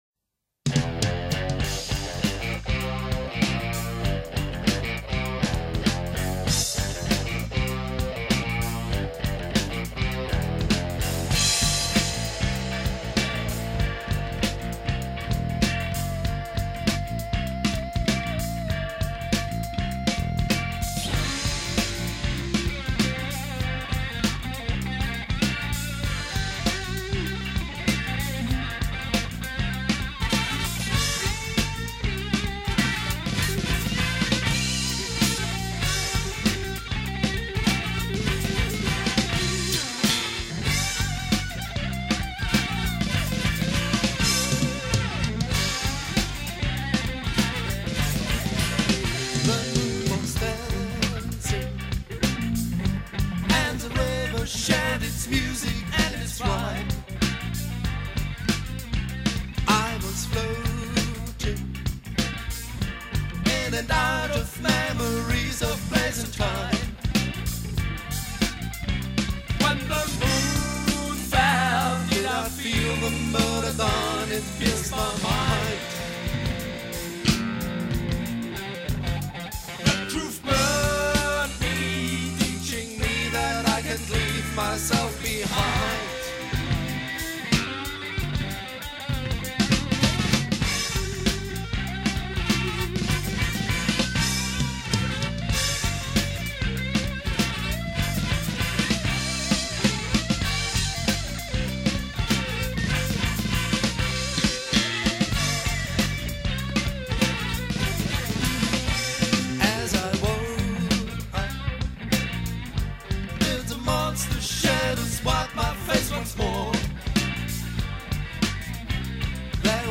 tanzbarer Funk-Titel
Studio: Graffiti-Studio München 1983 Text und Musik